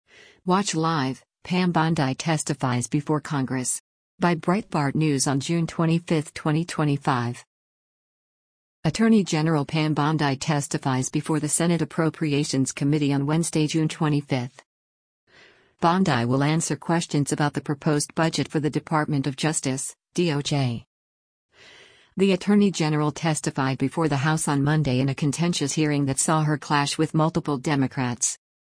Attorney General Pam Bondi testifies before the Senate Appropriations Committee on Wednesday, June 25.